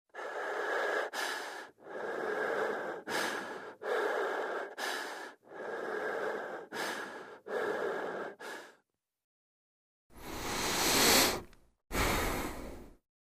Звуки запыхавшегося человека